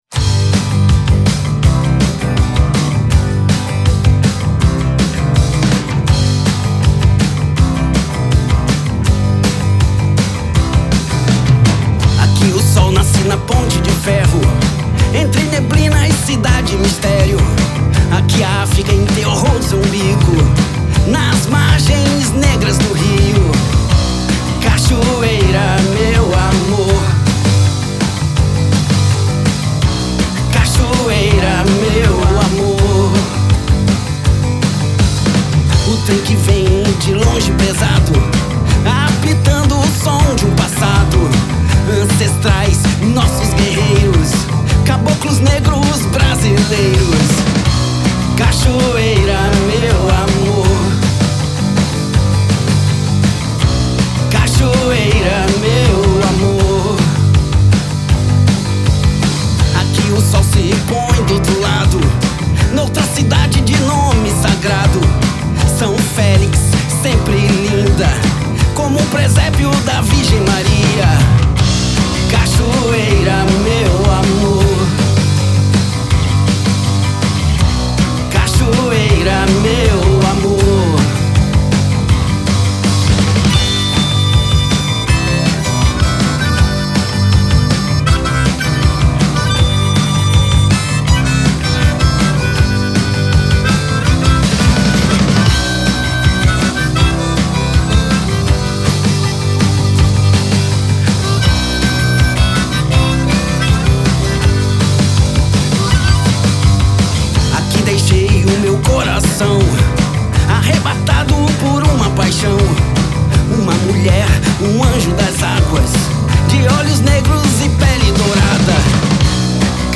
todas guiadas pelo violão de cordas de aço